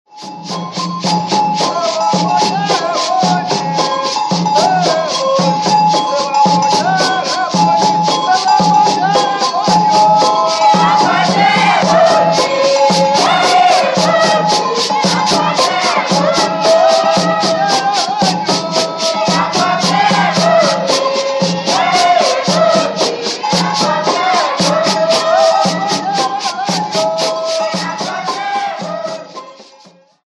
afoxe.mp3